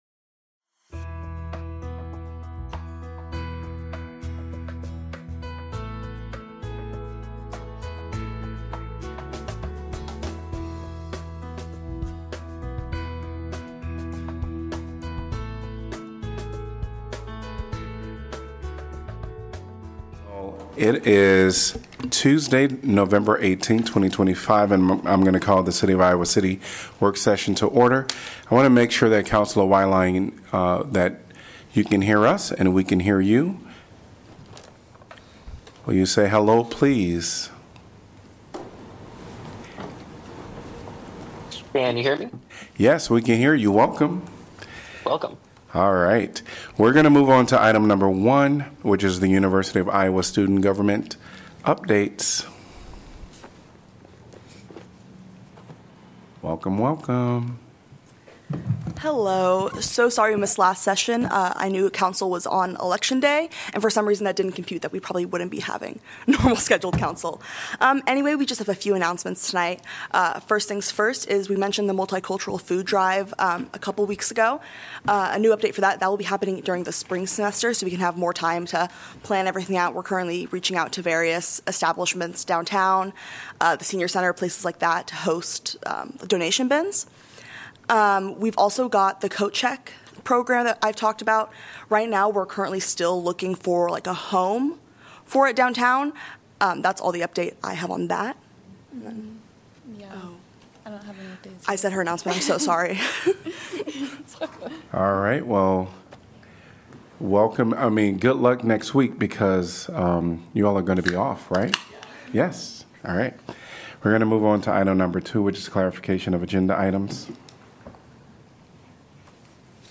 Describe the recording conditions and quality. Coverage of the Iowa City Council work session..